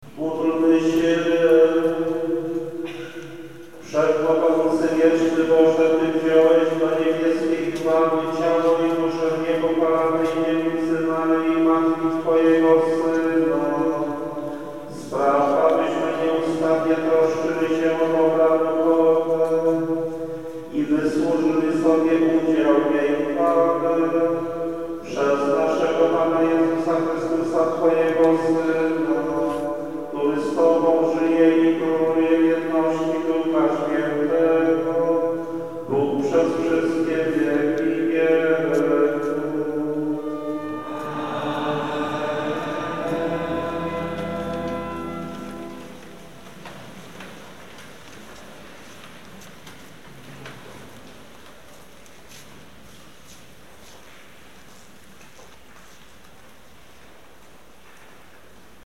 Nous sommes dans la cath�drale de briques d�Olsztyn, messe traditionnelle alors que nous nous attendions, pour ce jour particulier, � un office d�exception (voir Czestochowa). Mon sac � son est � mes pieds, enregistre. Nous sommes dans le fond, pr�s d'un confessionnal.
(On entend n�anmoins son murmure qu�un appareil sophistiqu� parviendrait peut-�tre � d� crypte r ... )